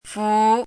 《襆》,襆的意思|襆的读音
注音： ㄈㄨˊ
fu2.mp3